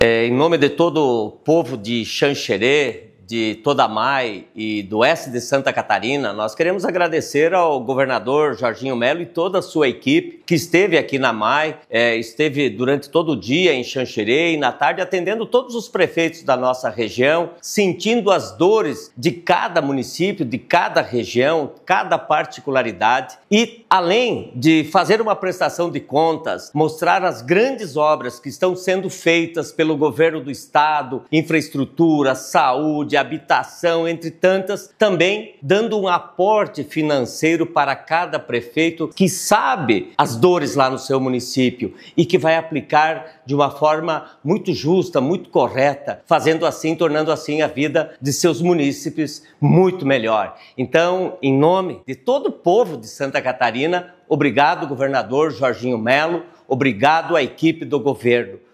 O encontro foi em em Xanxerê, no Oeste catarinense, com os municípios da Associação dos Municípios do Alto Irani (AMAI)
Após a conversa individual com o governador Jorginho Mello, o prefeito de Xanxerê, Oscar Martarello, destacou a importância de momentos como esse: